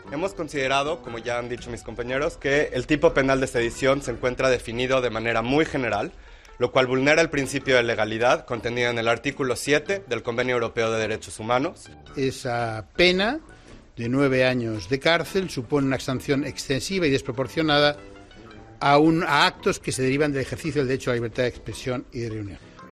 Declaraciones de los miembros de Amnistía Internacional